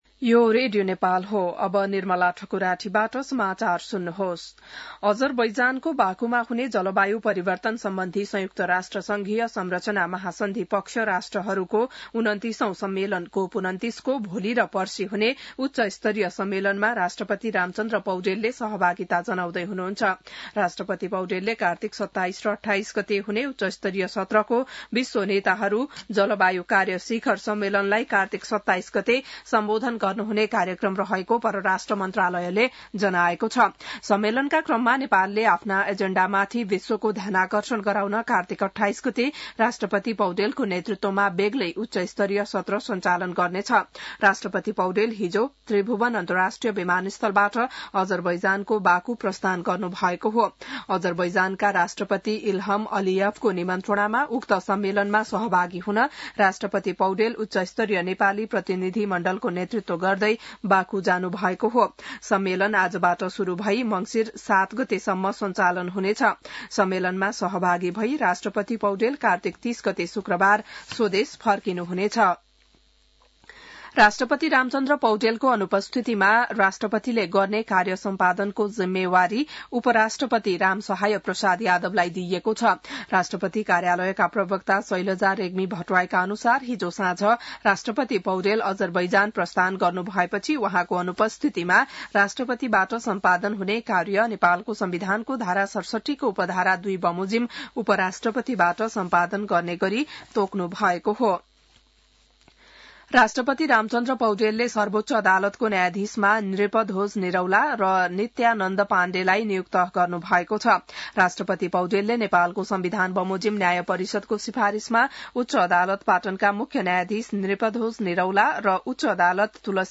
बिहान १० बजेको नेपाली समाचार : २७ कार्तिक , २०८१